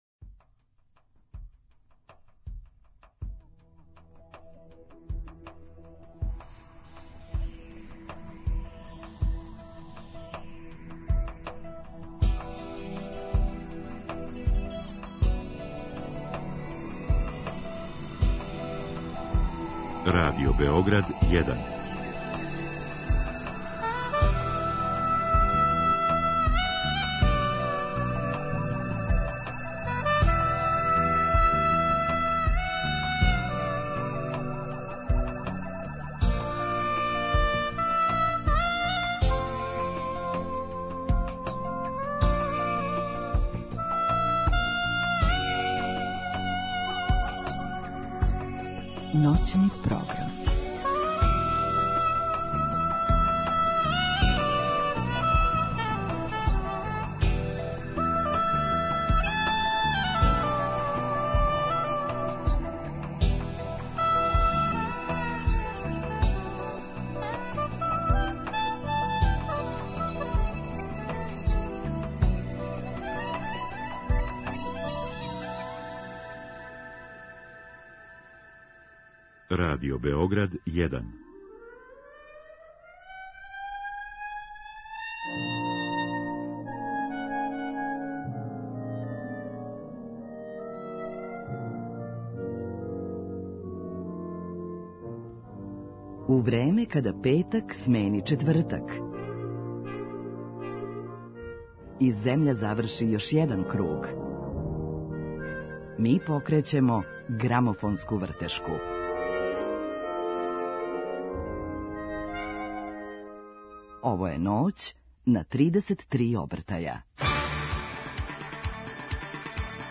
Имаћемо прилике да премијерно чујемо и њихов нови сингл.